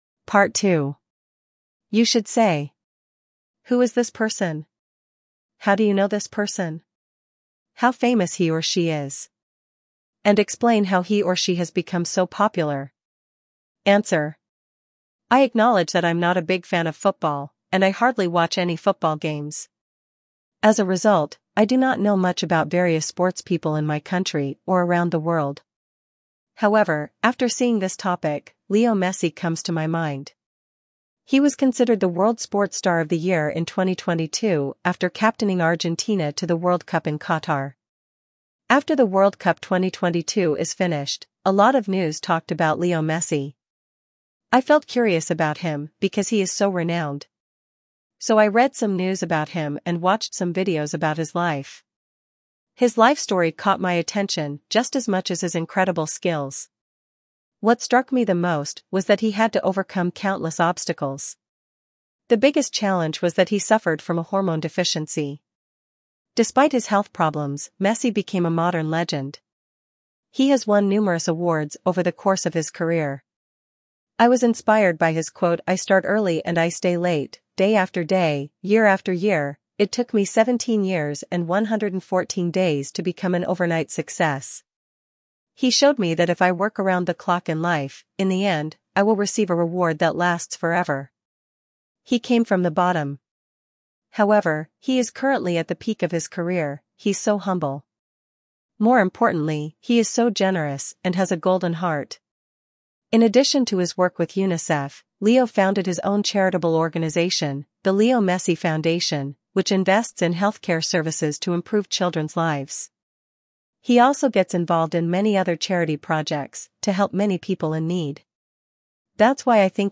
Jenny (English US)